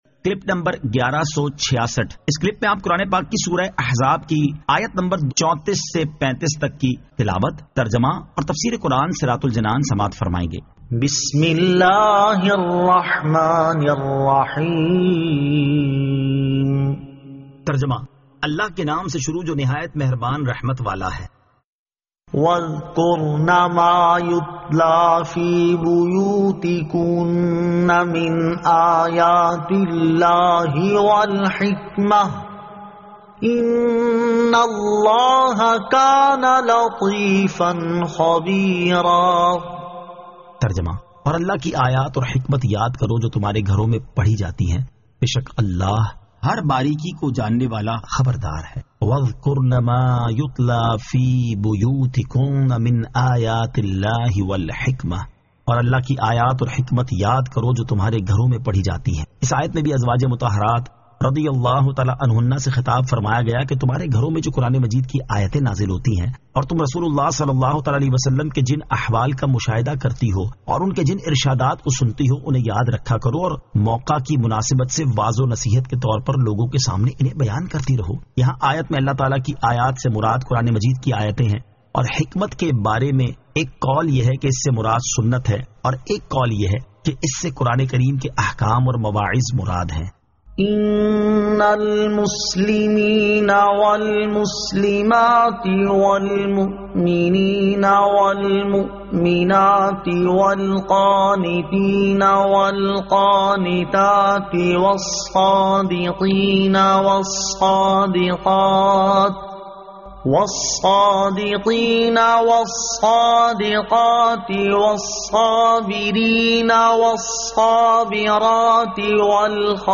Surah Al-Ahzab 34 To 35 Tilawat , Tarjama , Tafseer